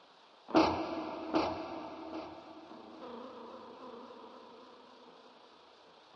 恐怖的声音 " 生物鸣叫
描述：听起来有点像海鸥的鸣叫和叫声，也可能是人类不应该知道的东西的声音？
用原声吉他和各种延迟效果制作而成。